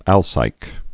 (ălsīk)